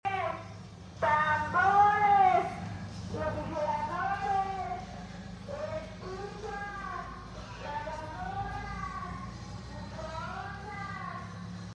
The truck thats been riding around Mexico City for 20 years playing the same advertisement! The back story is really cool.